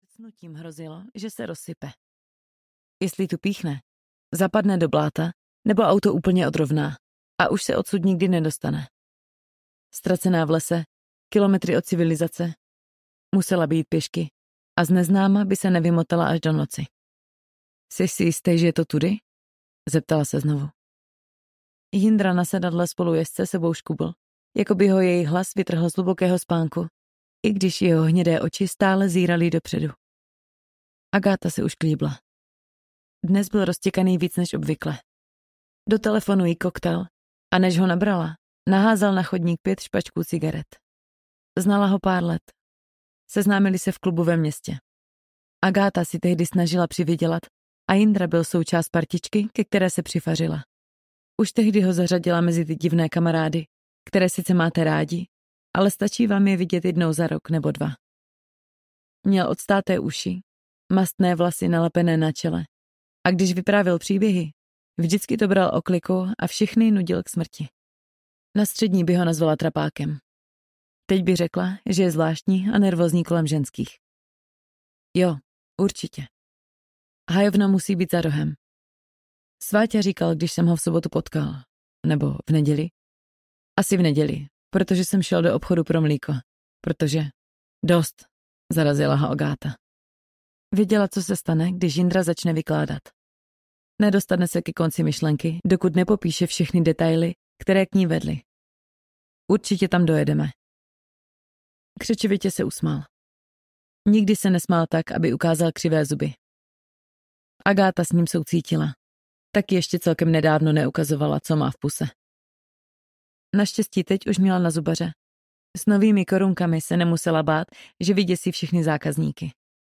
Ve větvích Yggdrasilu audiokniha
Ukázka z knihy